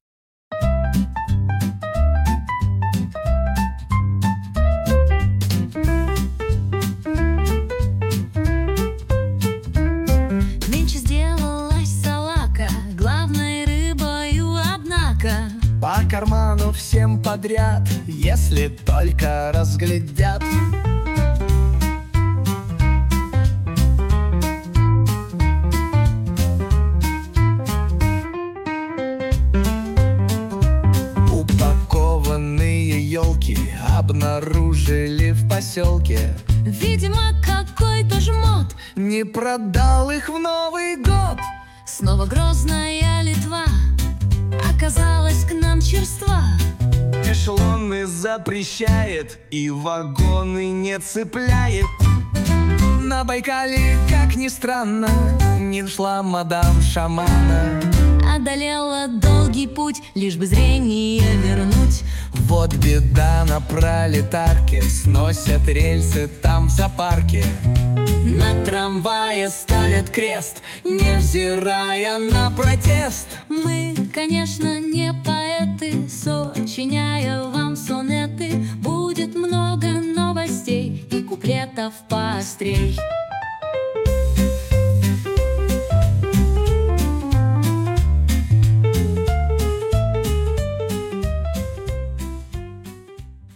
Комические куплеты о главных и важных событиях